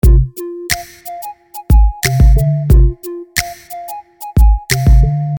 街头噪音和传教士
描述：弗吉尼亚州里士满的街头传教士。提到1940年代的德国，渐渐消失在街头乐队中。
标签： 布道者 里士满 街道 弗吉尼亚
声道立体声